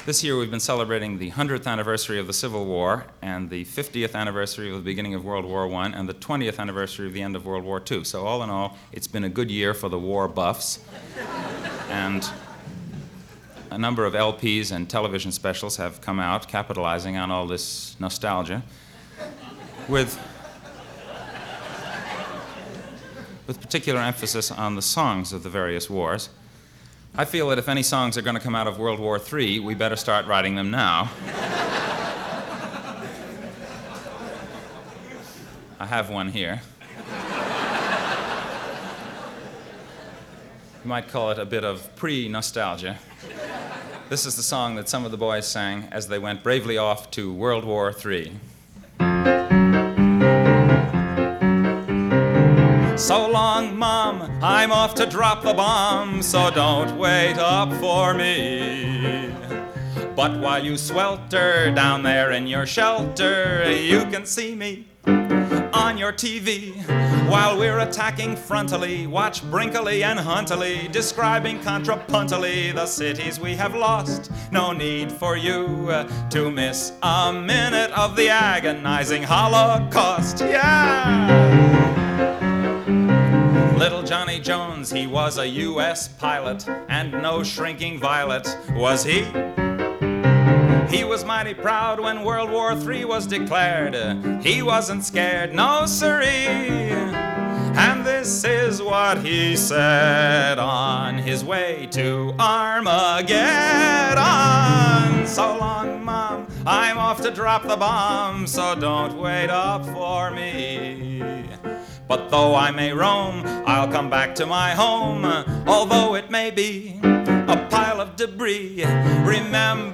I must start with two classics of comedy songs